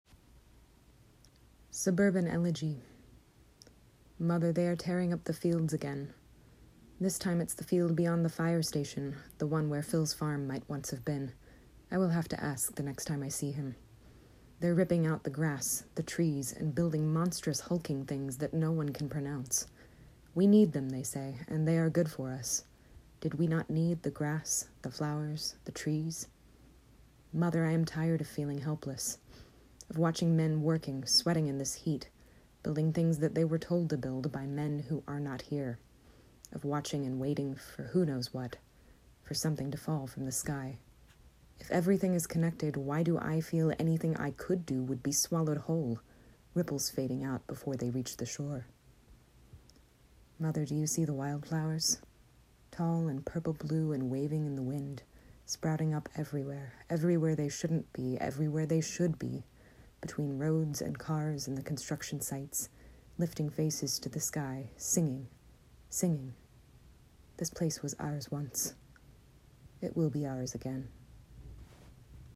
Listen to their reading of "Suburban Elegy"